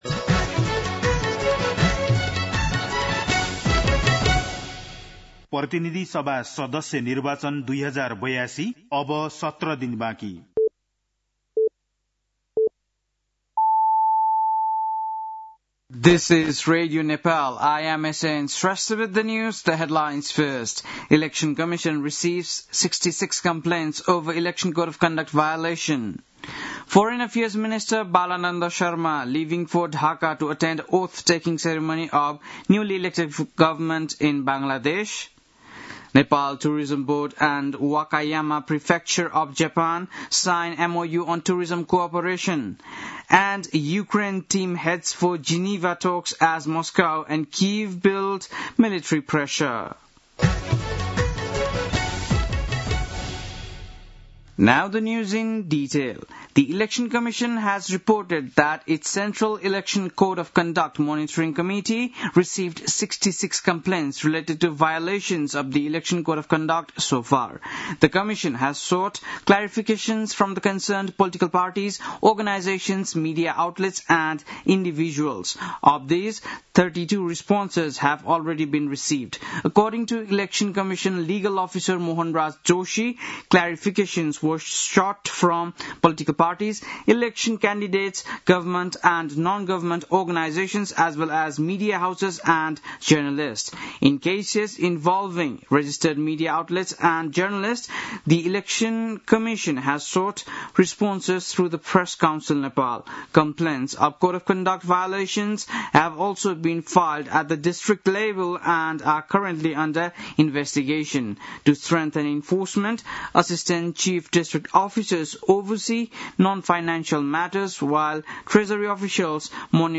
बेलुकी ८ बजेको अङ्ग्रेजी समाचार : ४ फागुन , २०८२
8-pm-english-news-11-04.mp3